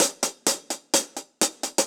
Index of /musicradar/ultimate-hihat-samples/128bpm
UHH_AcoustiHatB_128-05.wav